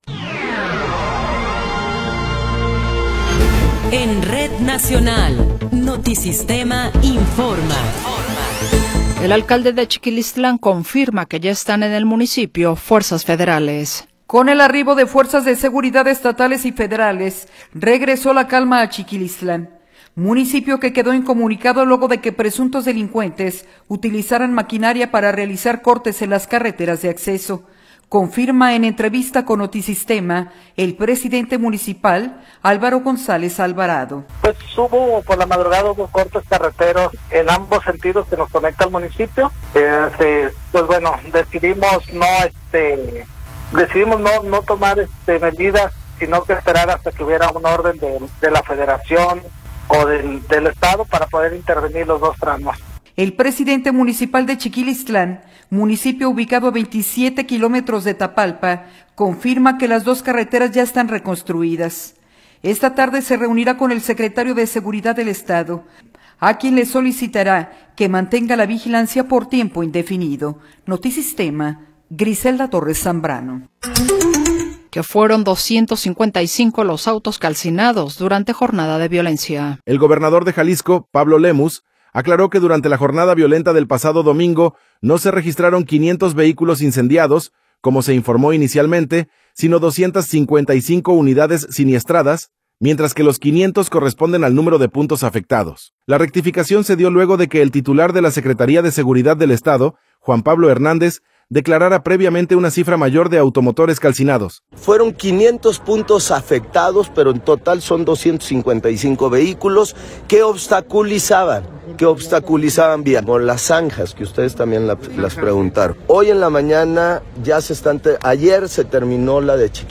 Noticiero 16 hrs. – 25 de Febrero de 2026
Resumen informativo Notisistema, la mejor y más completa información cada hora en la hora.